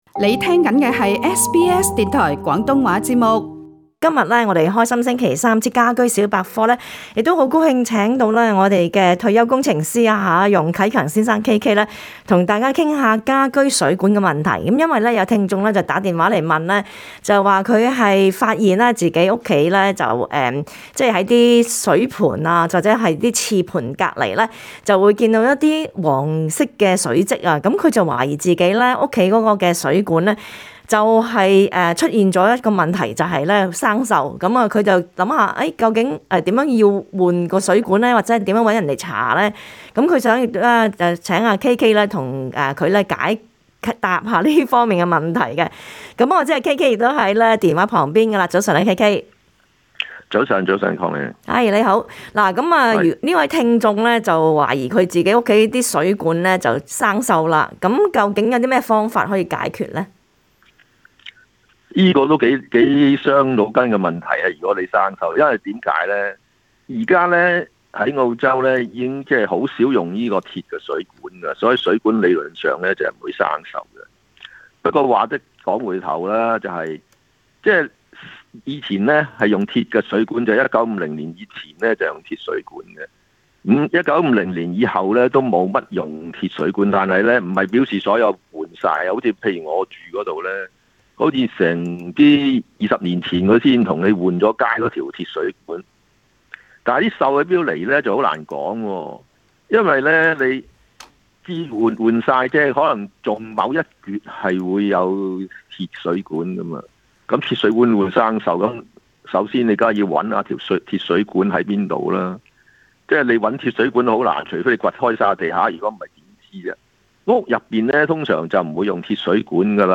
他亦會解答聽眾有關家居內一些維修的問題。